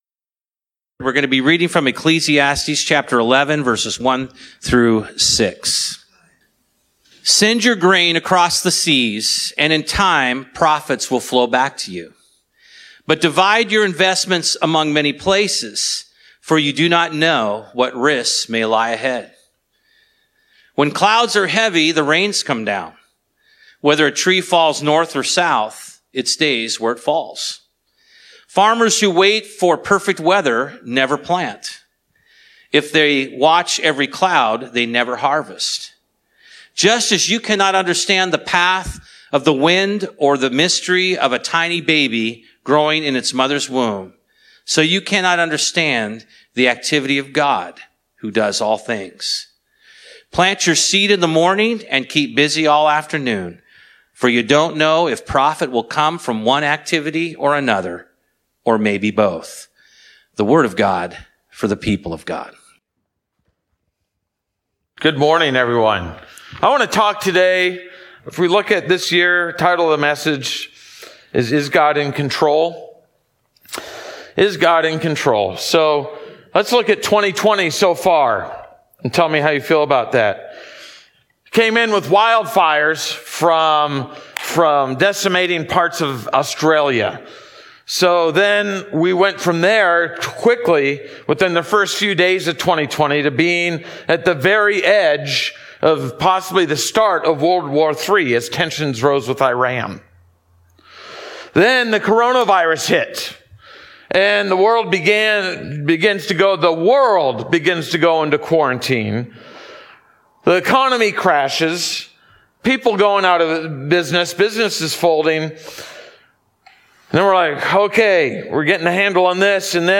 Bible Text: Ecclesiastes 11:1-6 | Preacher